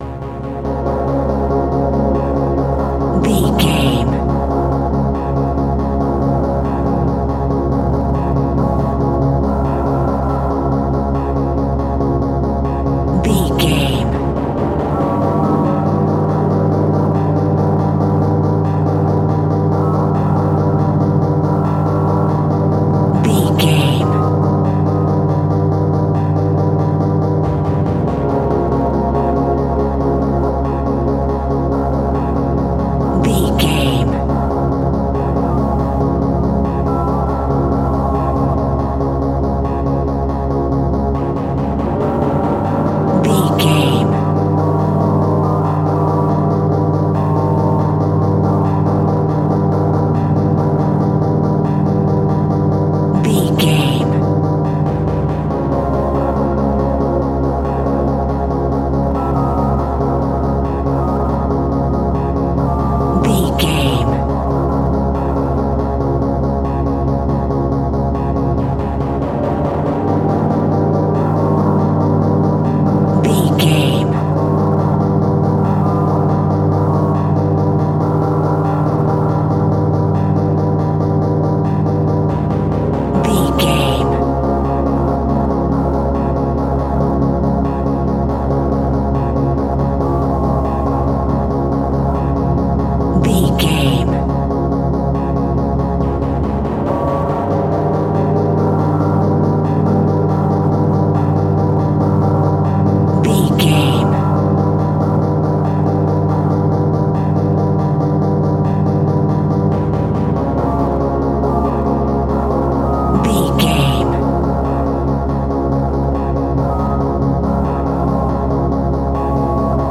In-crescendo
Aeolian/Minor
scary
ominous
dark
suspense
eerie
horror music
Horror Pads
horror piano
Horror Synths